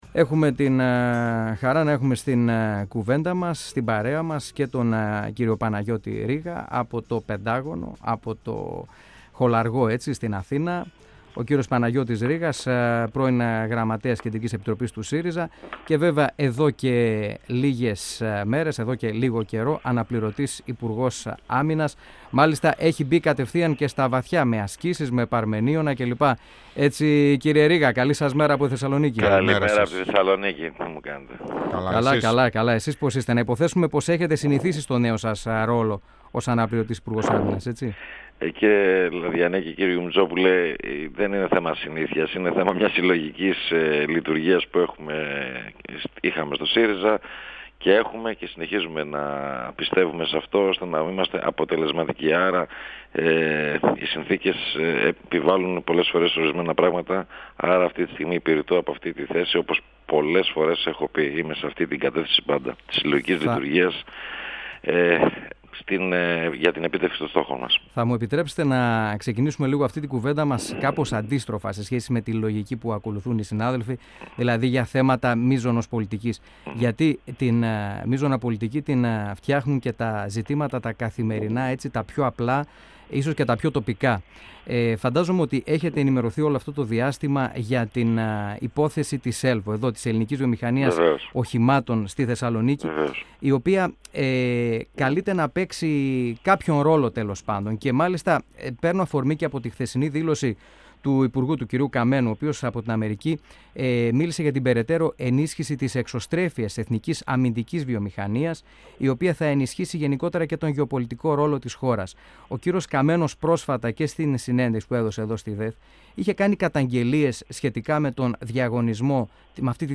Άμυνας σημείωσε, αναφερόμενος στη διαδικασία επιστροφής των αναδρομικών στα στελέχη των Ε.Δ.. πως αυτά θα δοθούν έως το τέλος του έτους. 102FM Συνεντεύξεις ΕΡΤ3